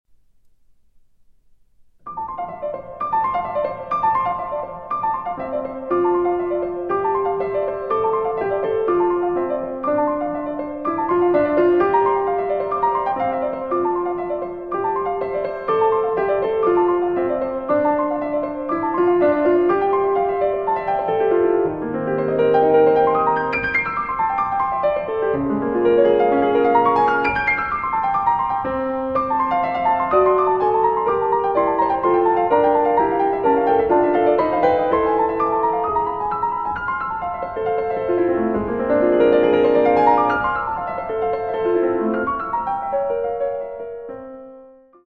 Leggierissimo con moto